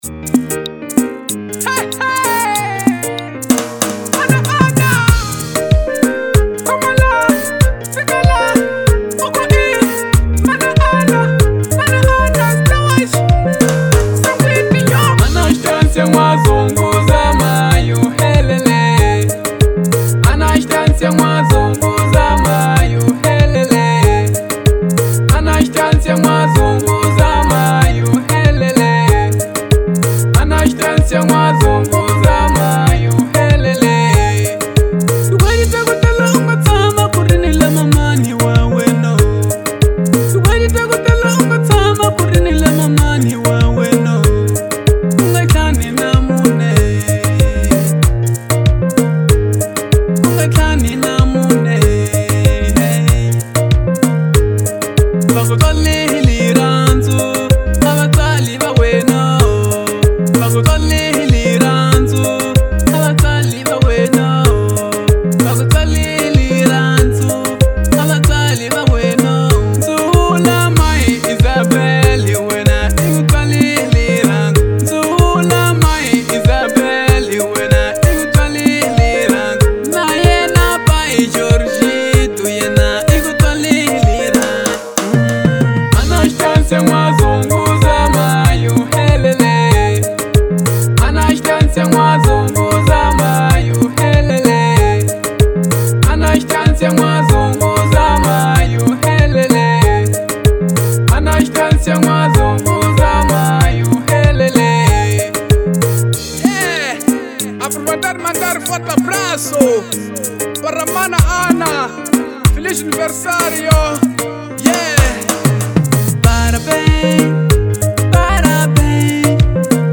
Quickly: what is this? Genre : Marrabenta